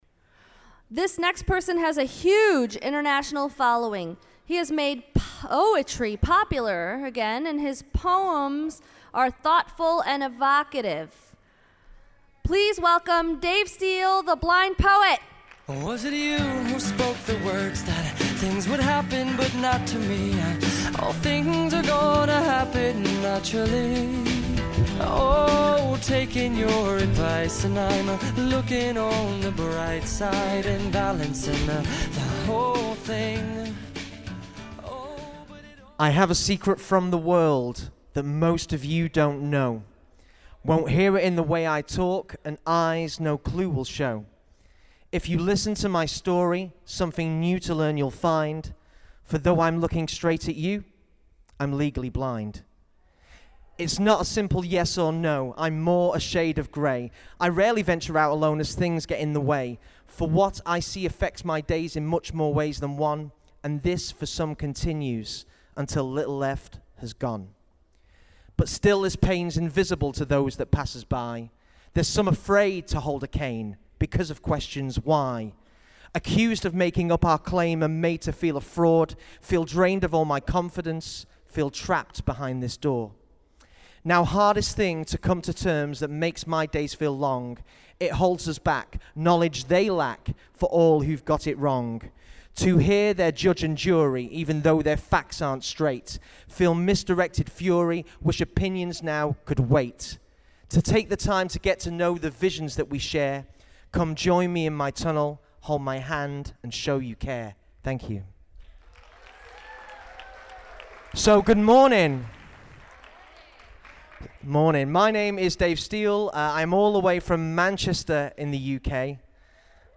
Please enjoy these highlights from the 2019 annual convention of the National Federation of the Blind of Maryland which was held November 8-10 inBaltimore.